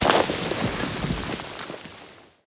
JSRS Sound Mod / gamedata / sounds / weapons / _boom / mono / dirt2.ogg
dirt2.ogg